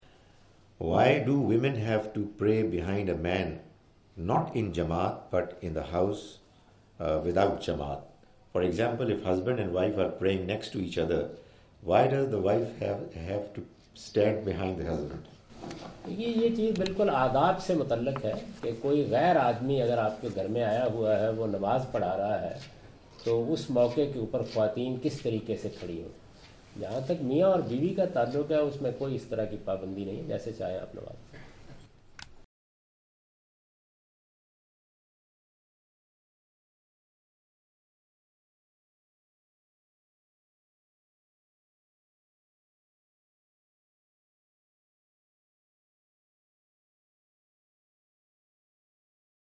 Javed Ahmad Ghamidi answer the question about "congregational prayer and women" during his visit to Manchester UK in March 06, 2016.
جاوید احمد صاحب غامدی اپنے دورہ برطانیہ 2016 کے دوران مانچسٹر میں "خواتین اور با جماعت نماز" سے متعلق ایک سوال کا جواب دے رہے ہیں۔